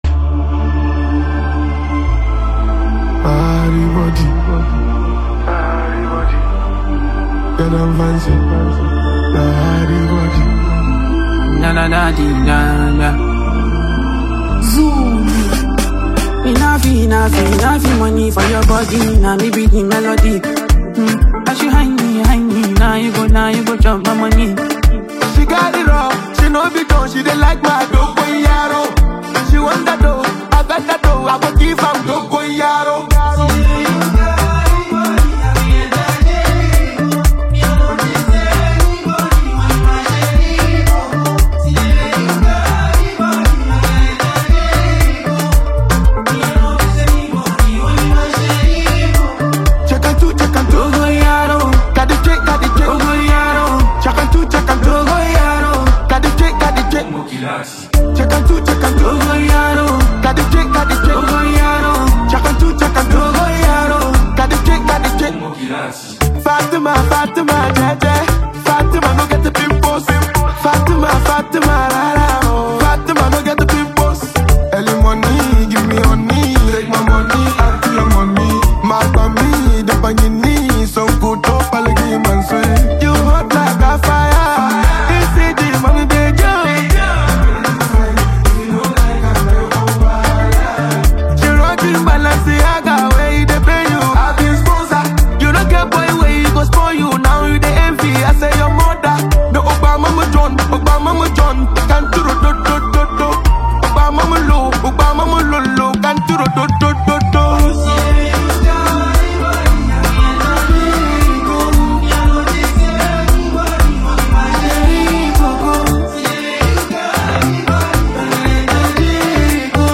Genre: Afrobeats / Indigenous